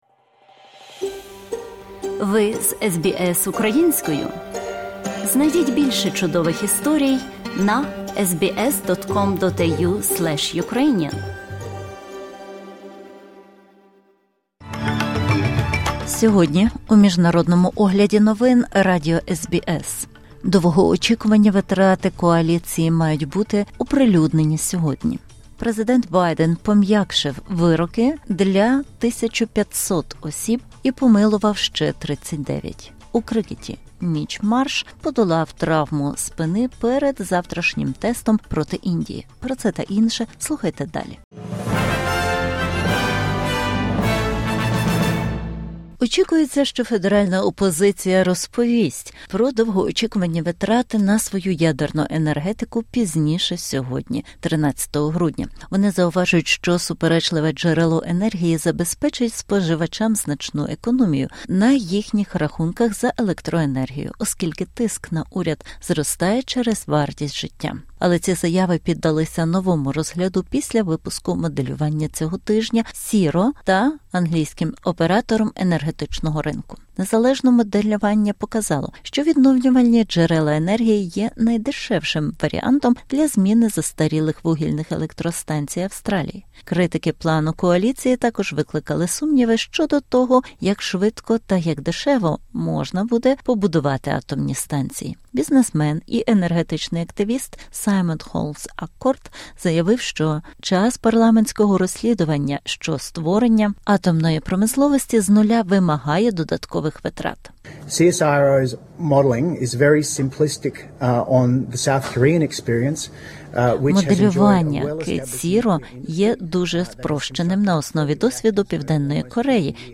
Огляд SBS новин українською мовою.